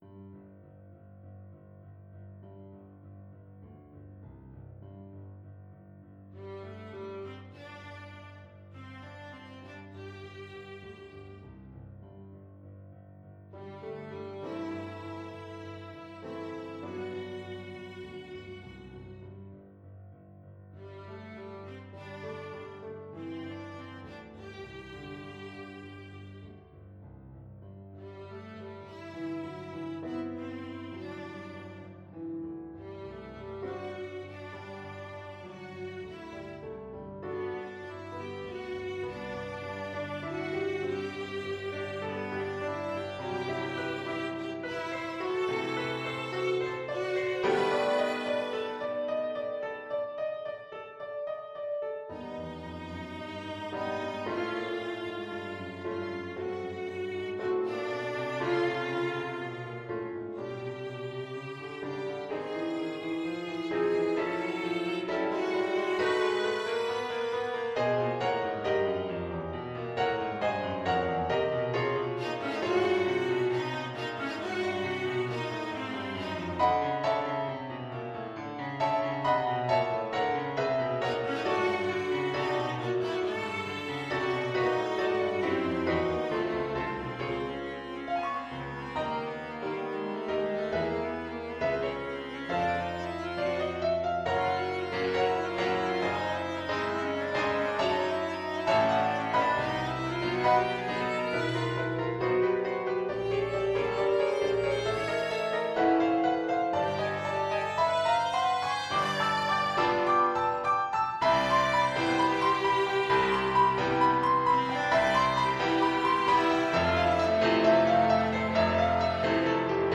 4/4 (View more 4/4 Music)
Moderato, with expectation =c.100
Classical (View more Classical Viola Music)